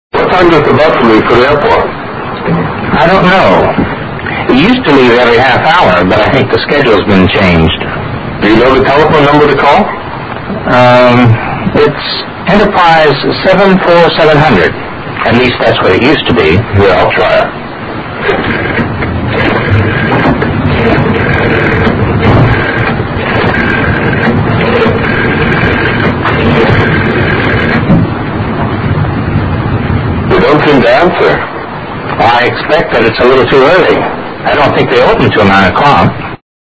英语对话听力mp3下载Listen 10:AIRPORT BUS
Dialogue 10